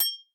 sqeeeek_bell_ting1
bell ding ping short ting sound effect free sound royalty free Sound Effects